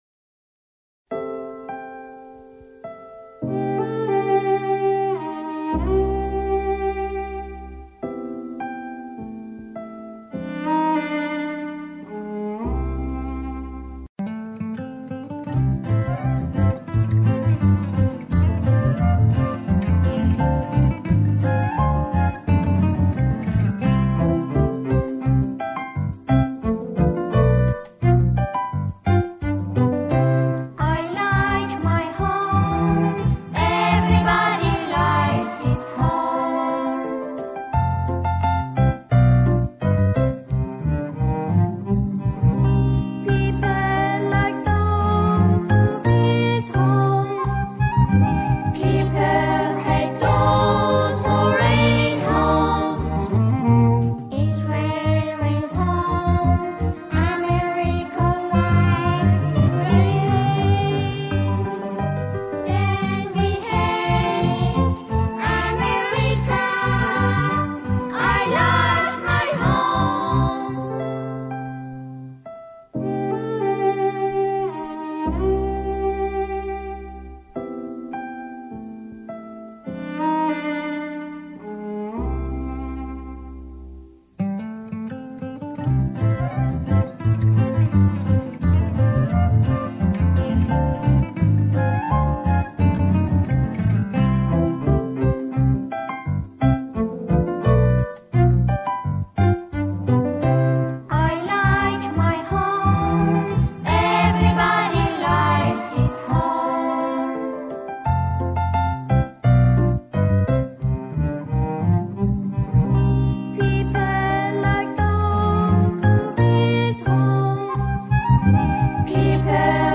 سرود های کودک
همخوانی شعری  کودکانه